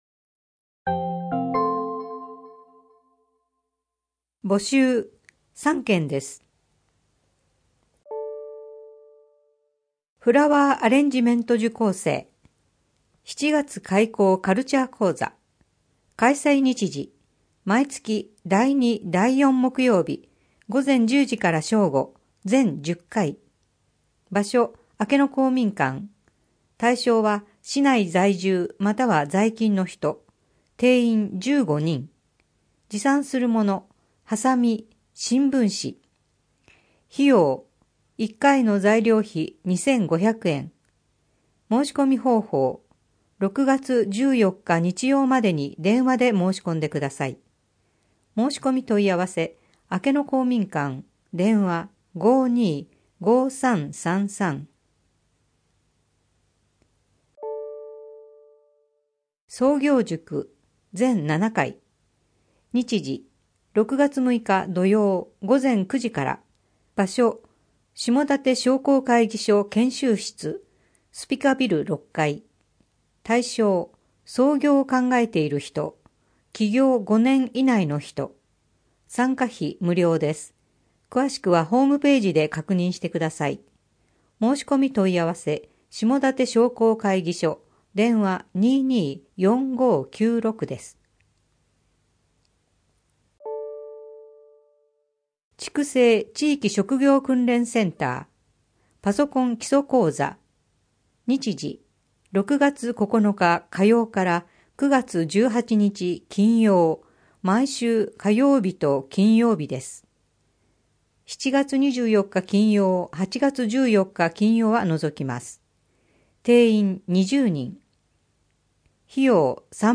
声の広報は、朗読ボランティア「野ばらの会」様のご協力により、目の不自由な人や高齢者など、広報紙を読むことが困難な人のために「声の広報筑西People」としてお届けしています。